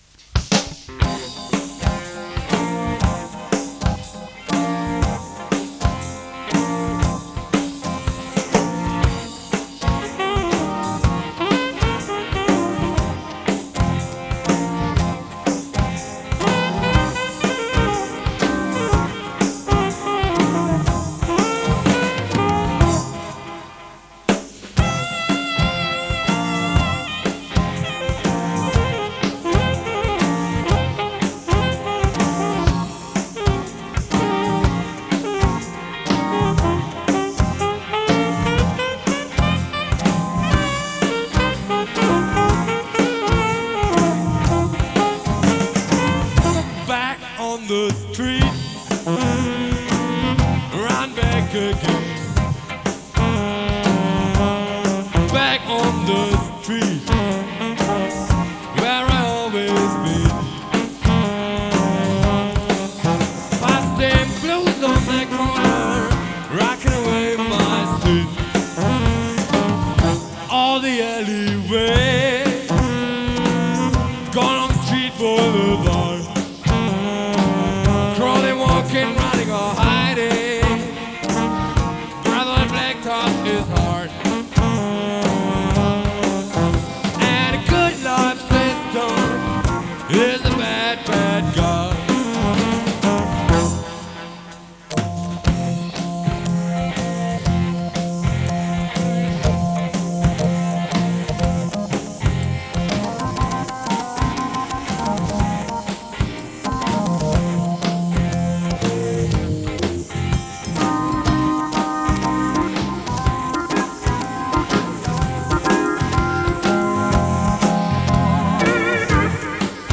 Und genießt ein geiles Sax!